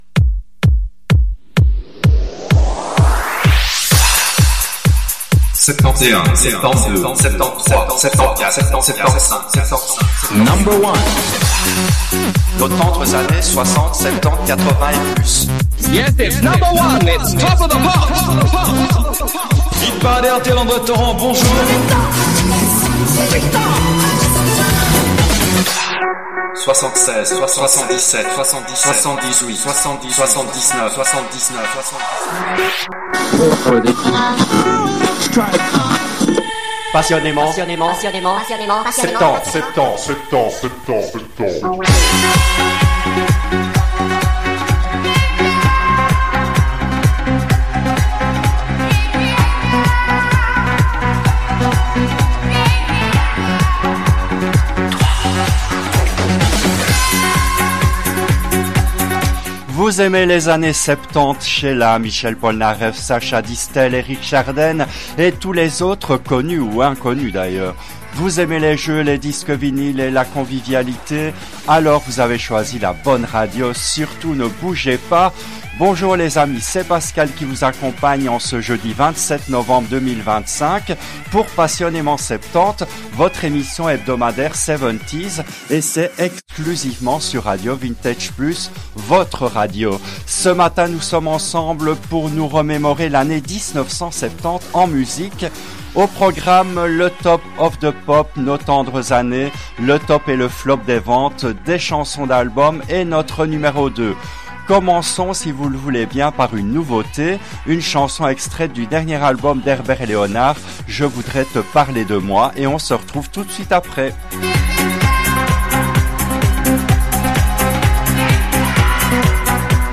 Cette émission a été diffusée en direct le jeudi 07 décembre 2023 à 10h depuis les studios belges de RADIO RV+.